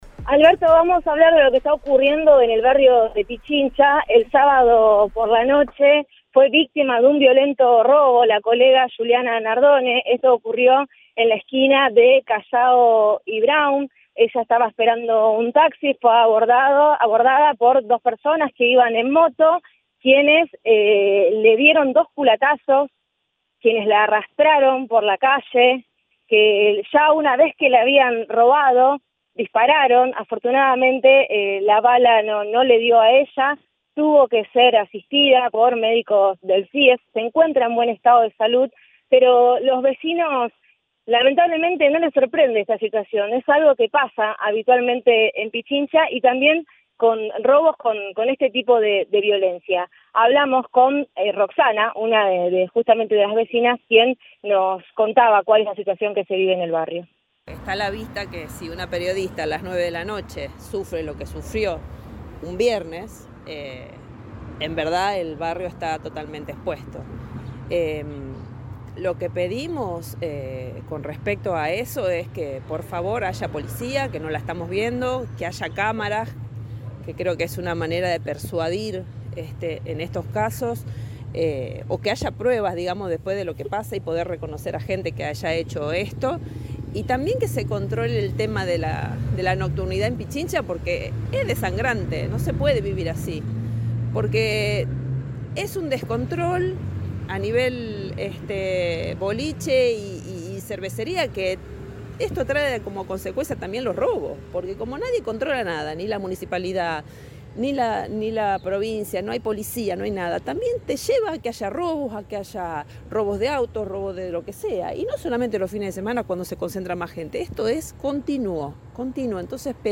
una vecina del barrio